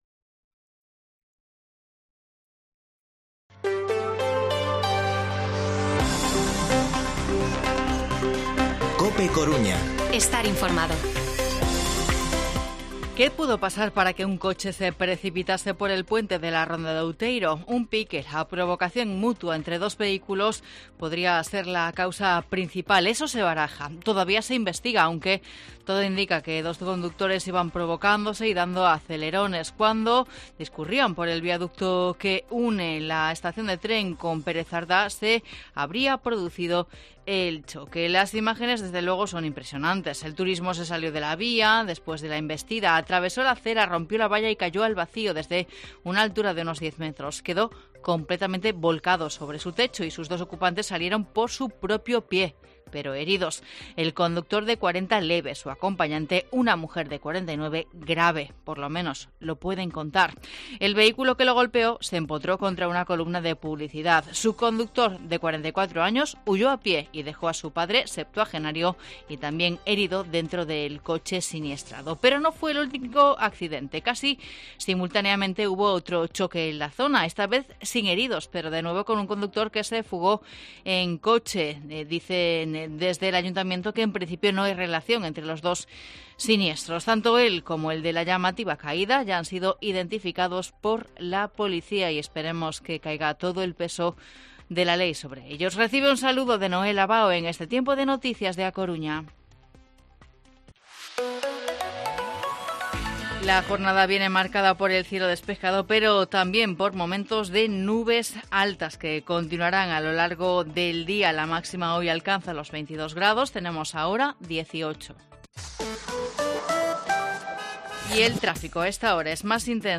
Informativo Mediodía COPE Coruña jueves, 14 de octubre de 2021 14:20-14:30h